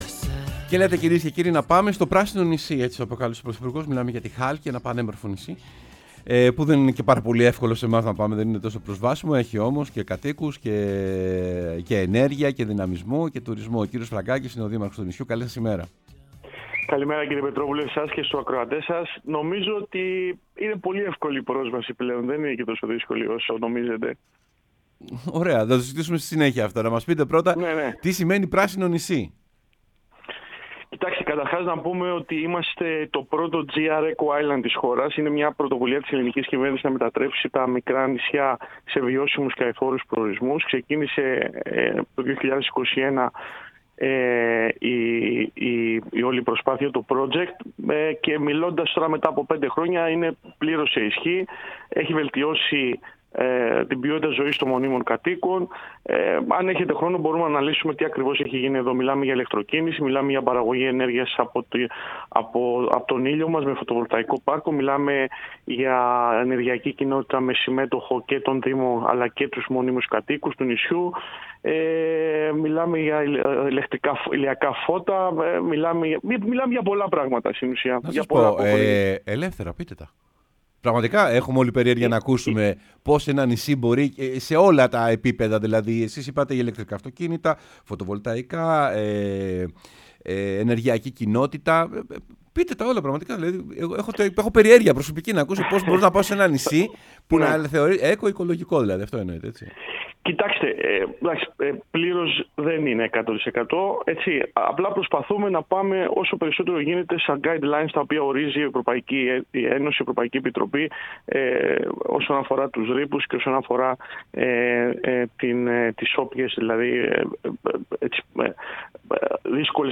Ευάγγελος Φραγκάκης, Δήμαρχος Χάλκης, μίλησε στην εκπομπή «Σεμνά και Ταπεινά»